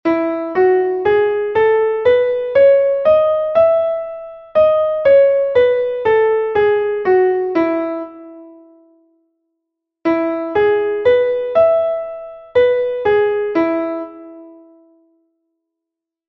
Entoación a capella
Melodía 5/8 en Mi M
Escala e arpexio:
escala_arpegio_MI_M.mp3